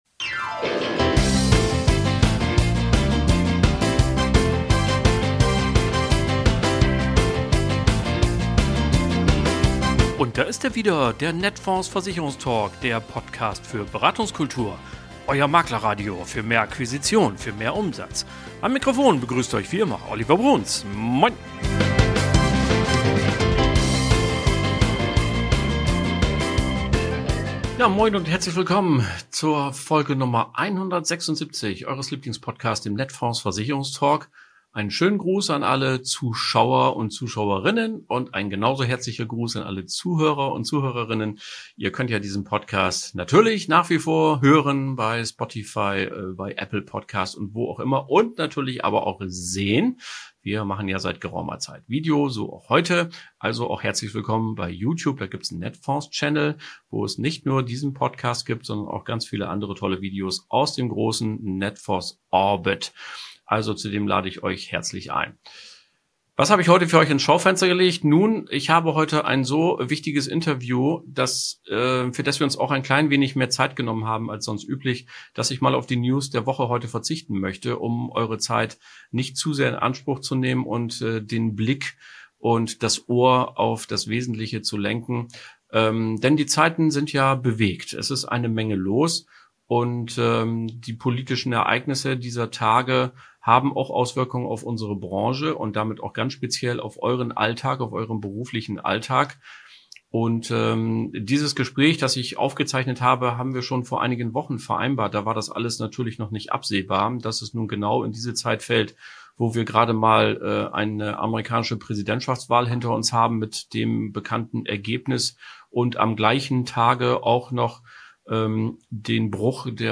Hören Sie spannende Interviews und Reportagen mit praktischen Tipps oder vertiefenden Hintergrund-Informationen.